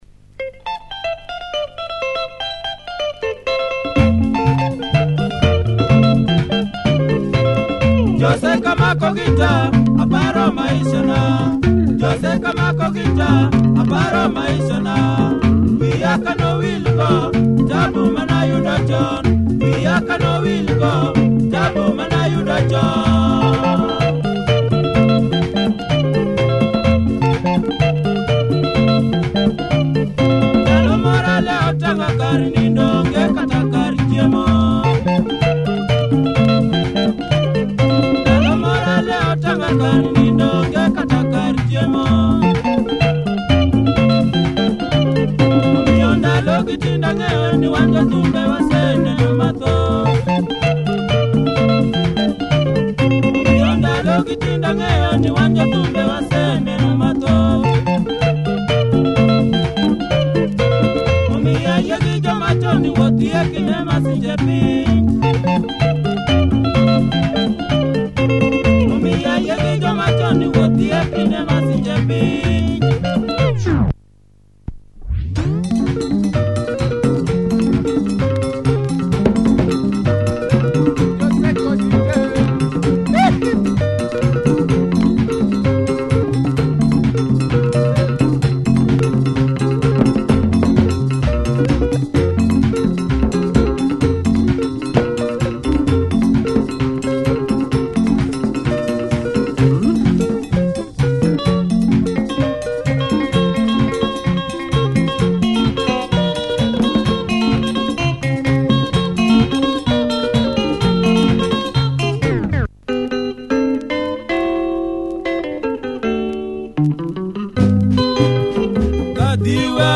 Nice Luo benga, nice percussive bouncy breakdown.
Nice percussive bouncy breakdown.